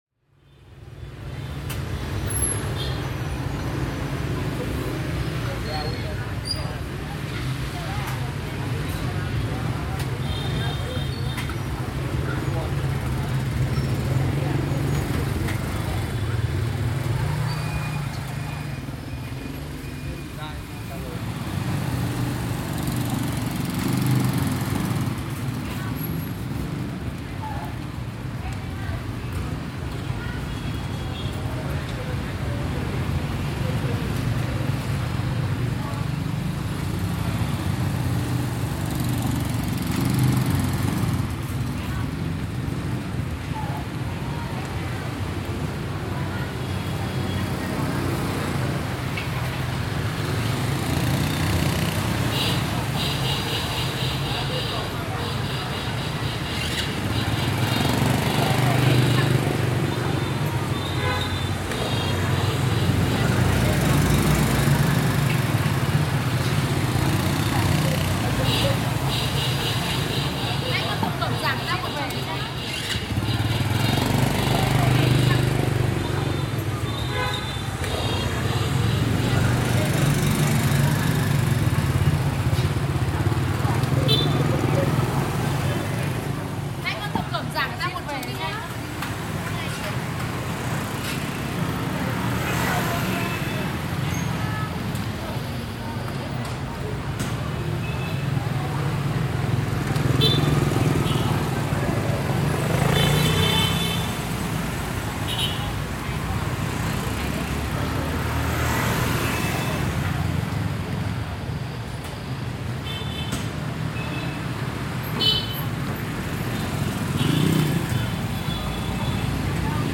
It is a crowded city characterized by relatively high noise exposure levels due to the large number of mopeds and harsh horn sounds.
Recorded in Hanoi, Vietnam